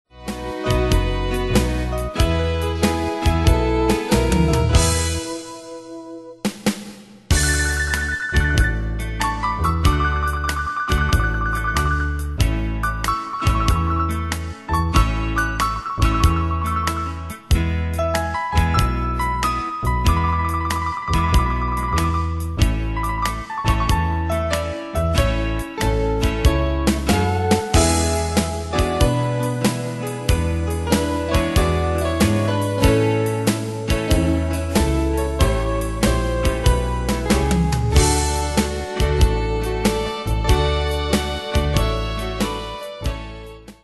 Style: Country Ane/Year: 1956 Tempo: 94 Durée/Time: 2.42
Pro Backing Tracks